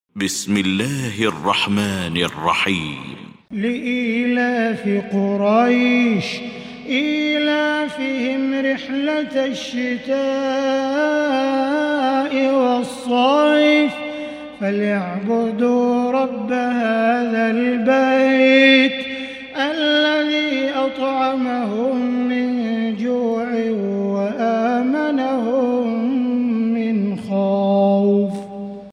المكان: المسجد الحرام الشيخ: فضيلة الشيخ عبدالله الجهني فضيلة الشيخ عبدالله الجهني قريش